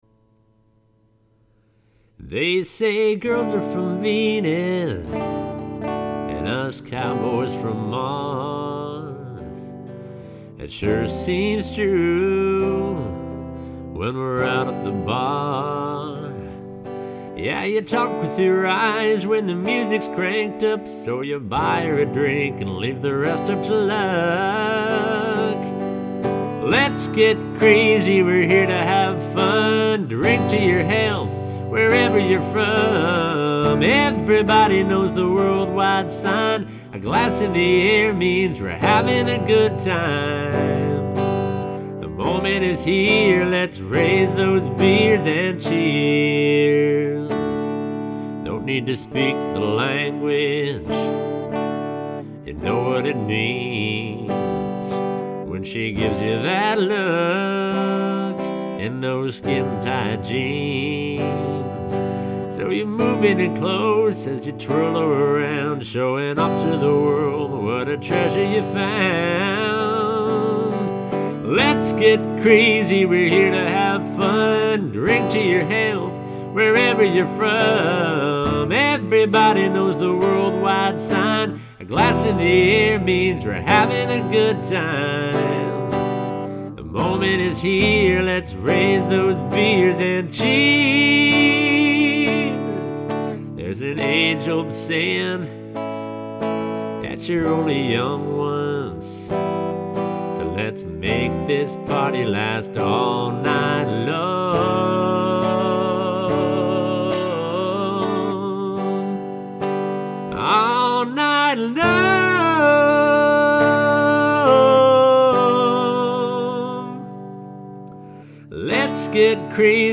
The Demo